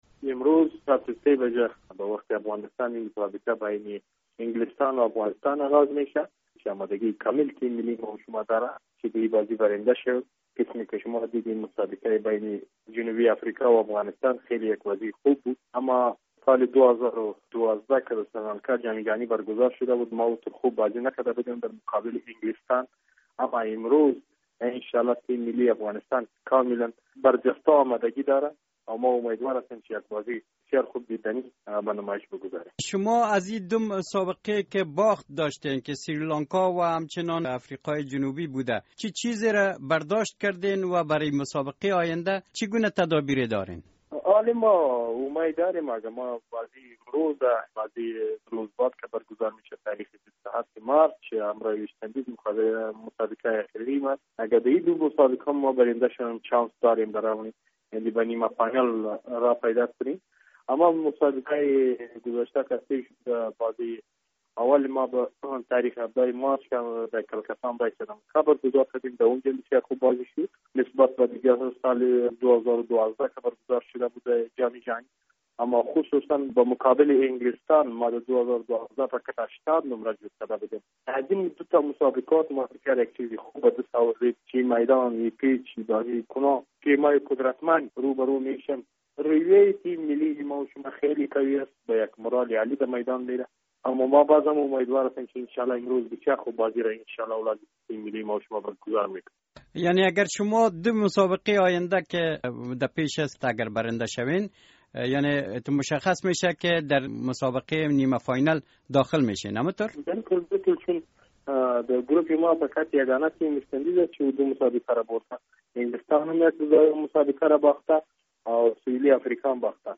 مصاحبه‌های ورزشی